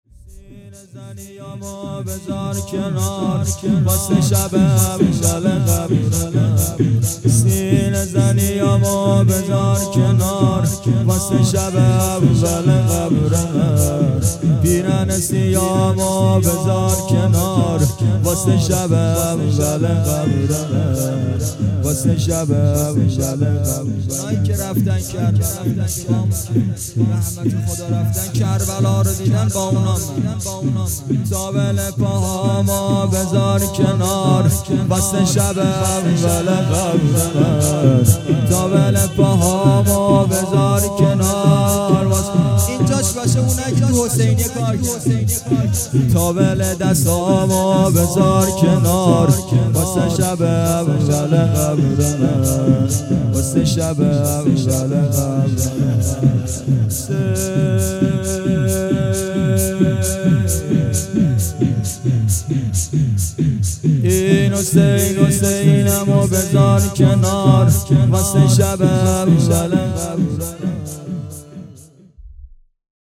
هیئت منتظران مهدی(عج)
شور- سینه زنی هامو بزار کنار
جلسه هفتگی 16تیرماه99